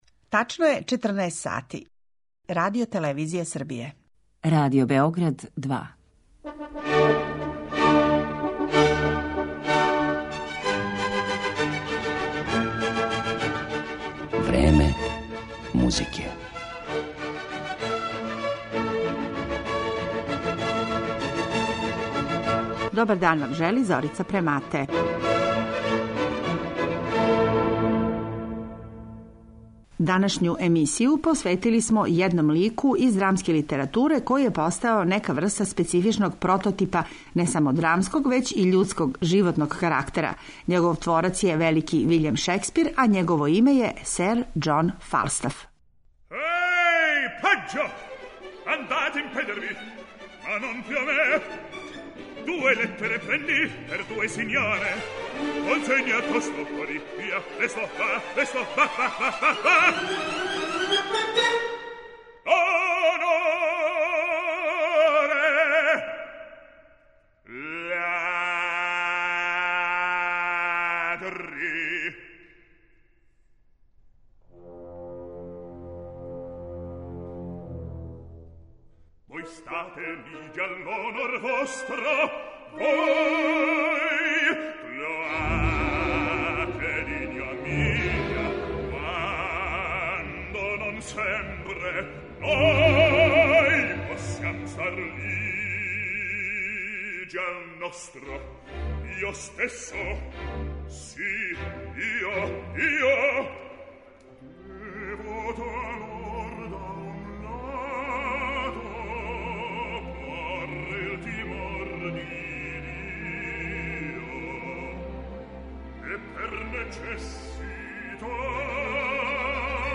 Сазнаћете ко се све у својим музичким остварењима бавио Фалстафовим ликом, а у емисији ћете чути музику из опера Ота Николаја, Ђузепа Вердија, Франца Лехара, Антонија Салијерија, као и Фалстафових сународника Вилијама Волтона и Едварда Елгара.